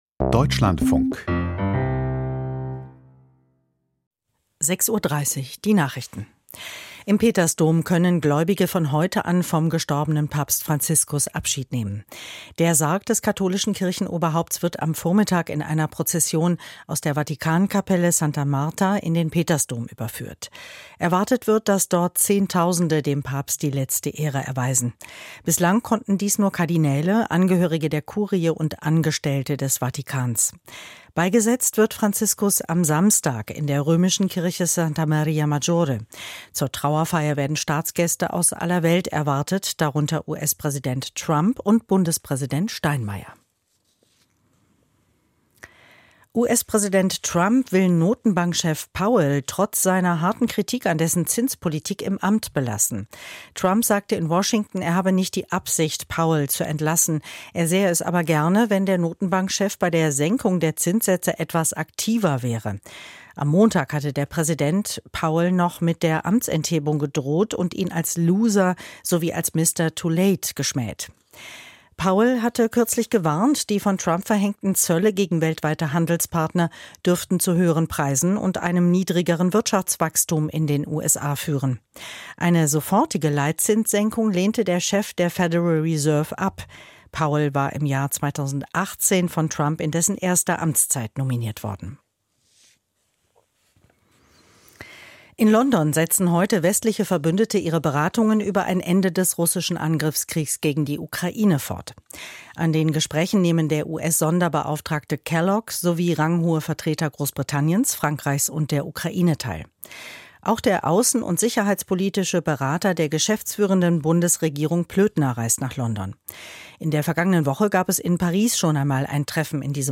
Die Deutschlandfunk-Nachrichten vom 23.04.2025, 06:30 Uhr